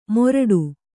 ♪ moraḍu